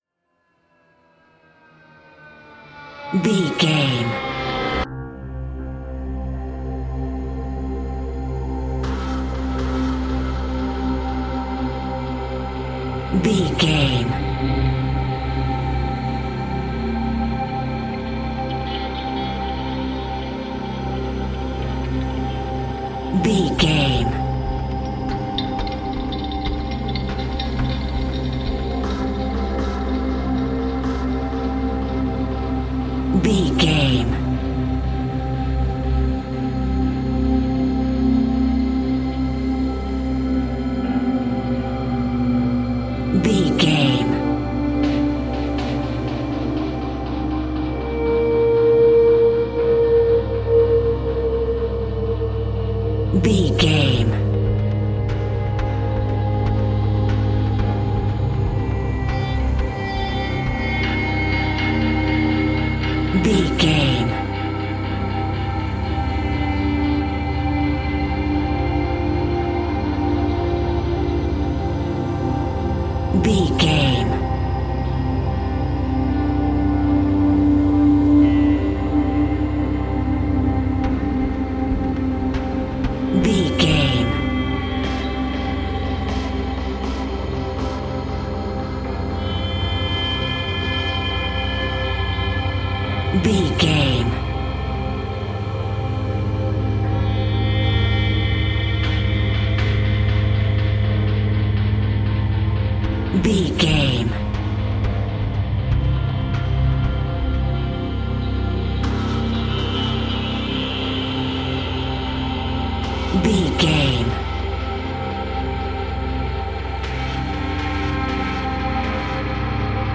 Aeolian/Minor
synthesiser
percussion
tension
ominous
dark
suspense
haunting
creepy